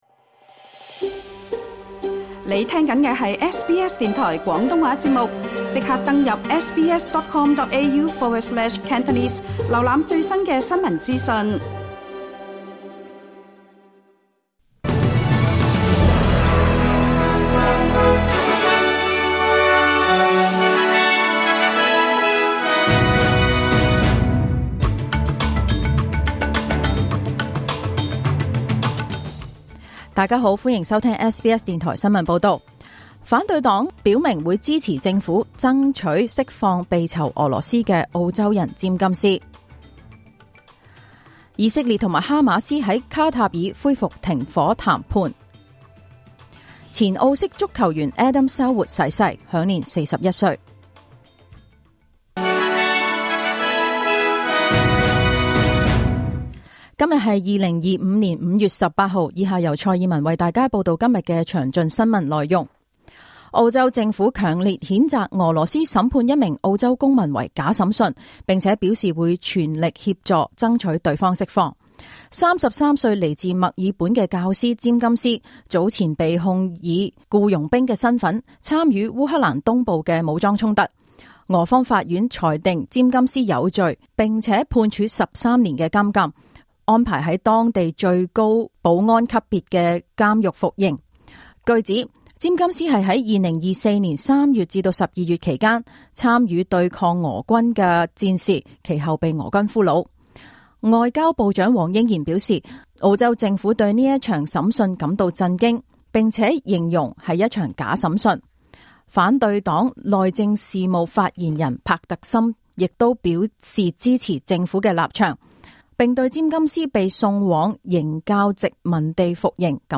2025 年 5 月 18 日 SBS 廣東話節目詳盡早晨新聞報道。